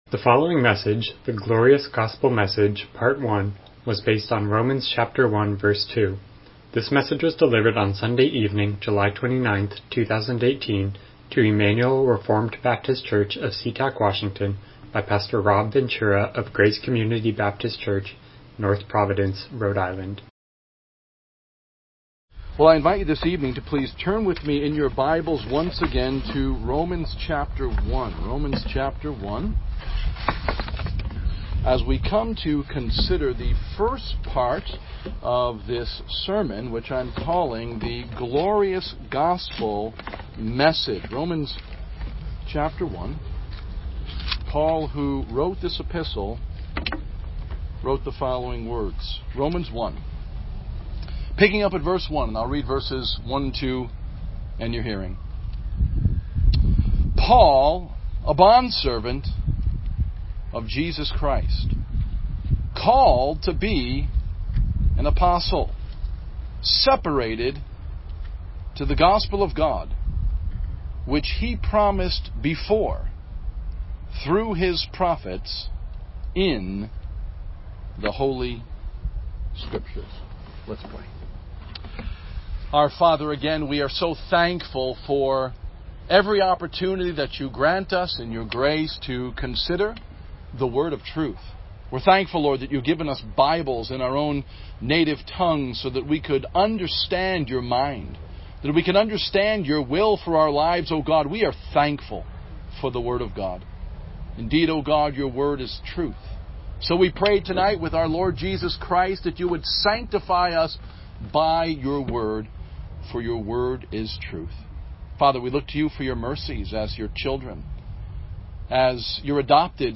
Romans 1:2 Service Type: Evening Worship « Paul